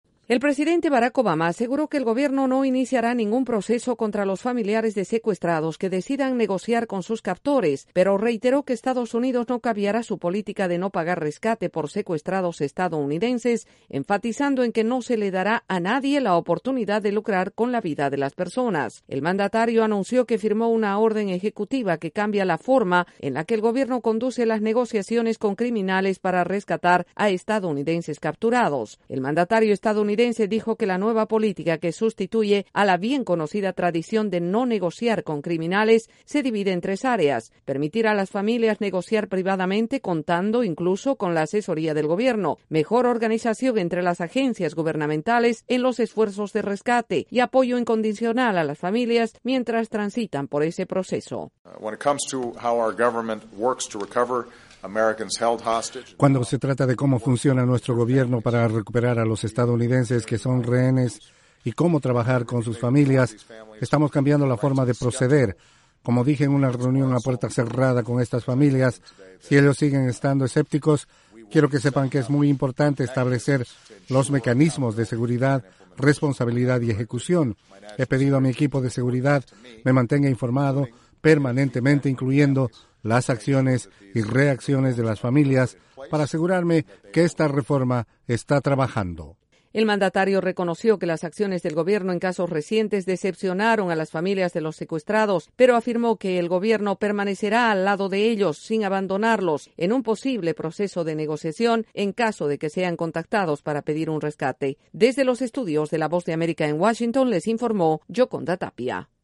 El presidente Barack Obama firmó una orden ejecutiva cambiando las políticas de rescate de estadounidenses secuestrados. Desde la Voz de América en Washington informa